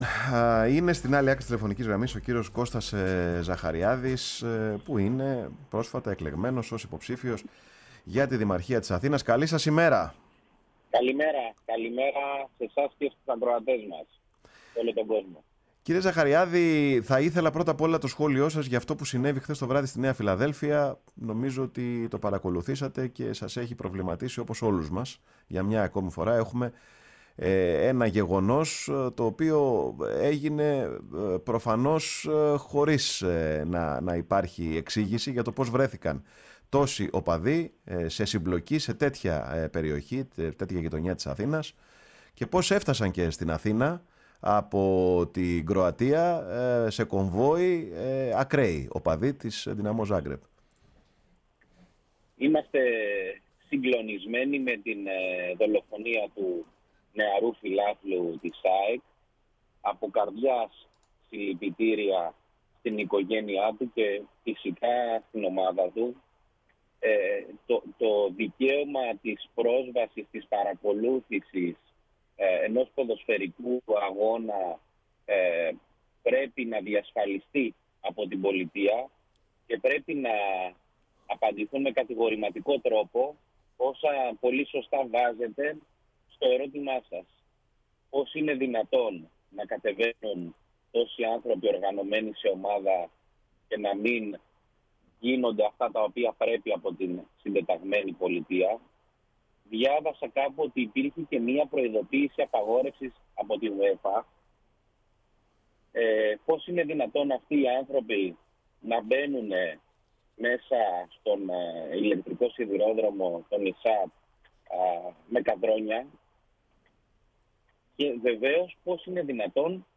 Στην εκπομπή της Τρίτης, 8 Αυγούστου, λίγο μετά τις 10:30, συνομιλήσαμε με τον κ. Κώστα Ζαχαριάδη, υποψήφιο με τον ΣΥΡΙΖΑ – Π.Σ. για τον δήμο της Αθήνας, σε μία από τις πρώτες του συνεντεύξεις από τη στιγμή που πήρε το χρίσμα.
Αναφέρθηκε στα λάθη της διαχείρισης που έγινε από τον Κώστα Μπακογιάννη , ενώ ξεκαθάρισε ότι το ζήτημα με την οδό Πανεπιστημίου πρέπει να αντιμετωπιστεί ως μέρος ενός συνολικού ανασχεδιασμού που θα γίνει για την κυκλοφορία των οχημάτων και τον δημόσιο χώρο στην πόλη της Αθήνας. Η ΦΩΝΗ ΤΗΣ ΕΛΛΑΔΑΣ Παρε τον Χρονο σου ΕΝΗΜΕΡΩΣΗ Ενημέρωση ΣΥΝΕΝΤΕΥΞΕΙΣ Συνεντεύξεις Δημος Αθηναιων δημοτικες εκλογες 2023 Κωστας Ζαχαριαδης